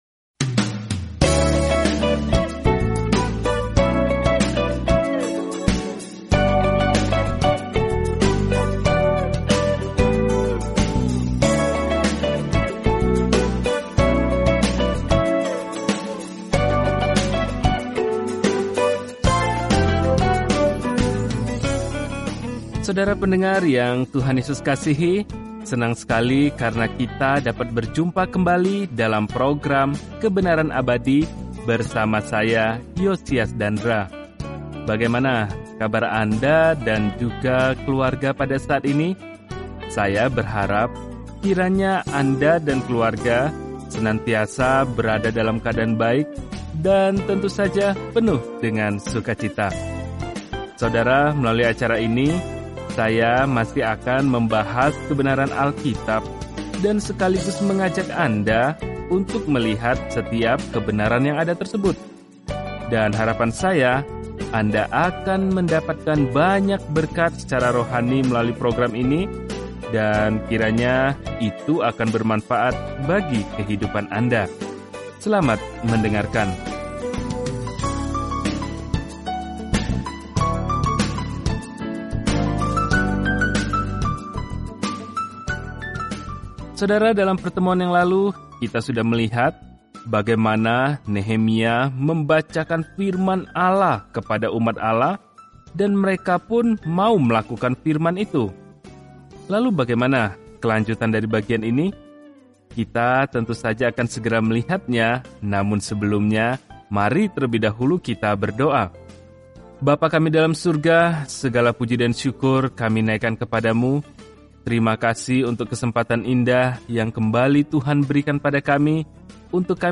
Firman Tuhan, Alkitab Nehemia 9 Hari 10 Mulai Rencana ini Hari 12 Tentang Rencana ini Ketika Israel kembali ke tanah mereka, kondisi Yerusalem buruk; seorang manusia biasa, Nehemia, membangun kembali tembok di sekeliling kota dalam buku Sejarah terakhir ini. Telusuri Nehemia setiap hari sambil mendengarkan pelajaran audio dan membaca ayat-ayat tertentu dari firman Tuhan.